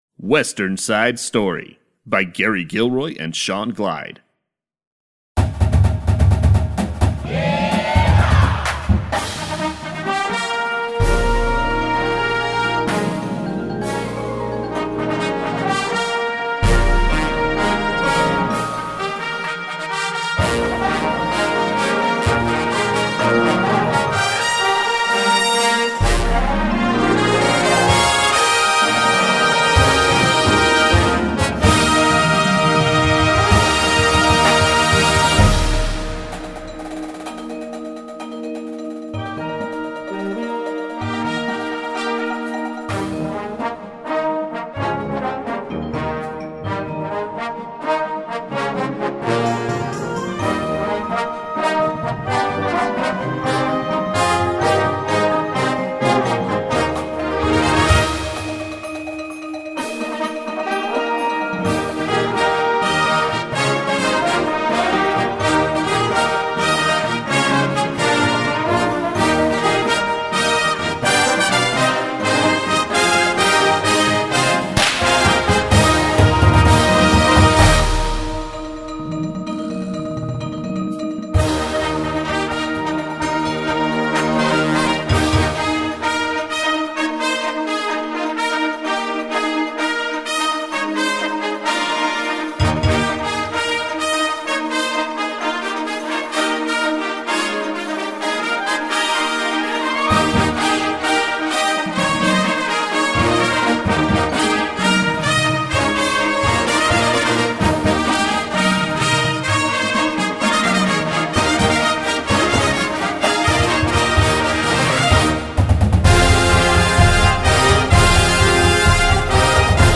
western themed Marching Band Show